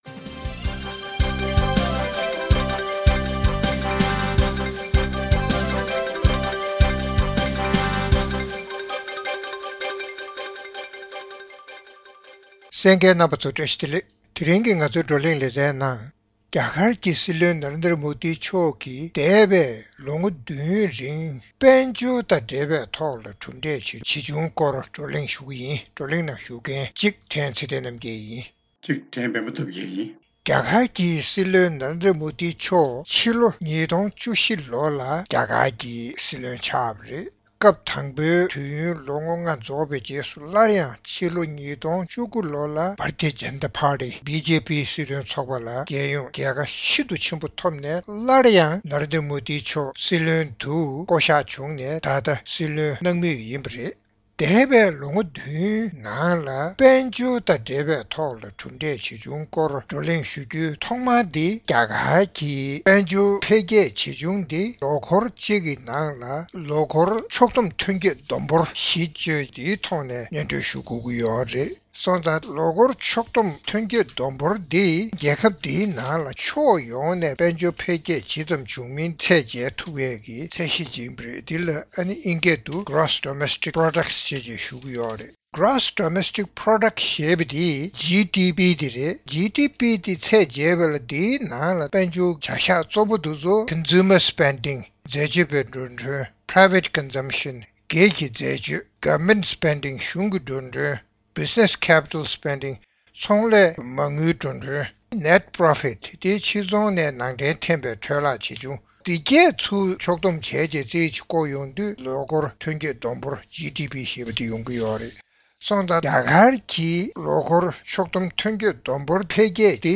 རྩོམ་སྒྲིག་པའི་གླེང་སྟེགས་ཞེས་པའི་ལེ་ཚན་ནང་།རྒྱ་གར་ནང་འདས་པའི་ལོ་ངོ་བདུན་རིང་ཏོག་དབྱིབས་ནད་ཡམས་ཀྱིས་རྐྱེན་པས་དཔལ་འབྱོར་འཕེལ་རྒྱས་དམའ་བ་དང་། ཡུལ་ཐང་འཕར་ཆ་མཐོ་བ། འཚོ་རྟེན་ལས་ཀ་ཉུང་བའི་དཀའ་ངལ་སྐོར་བགྲོ་གླེང་གནང་བ་གསན་རོགས་གནང་།